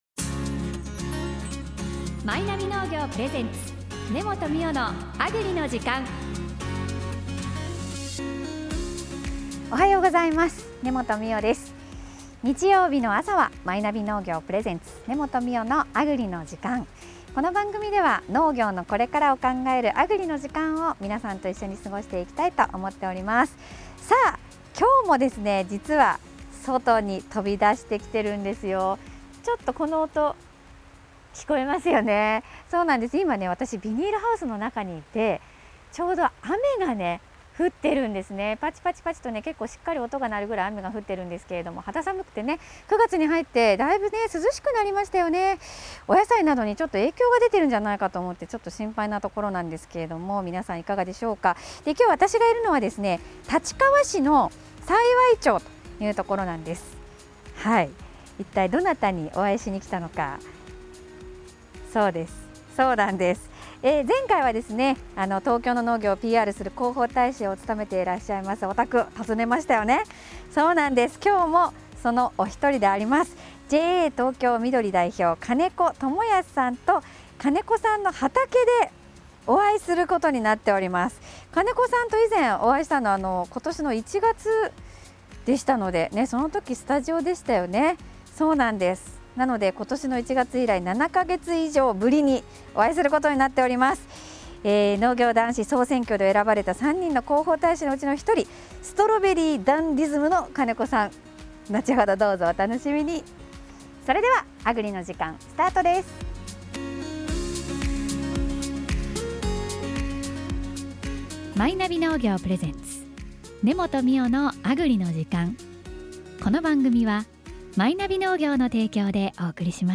立川市の幸町にあるビニールハウスを訪ねました。雨音が時折り激しくハウスの屋根を叩く中、楽しいおしゃべりがはずみました。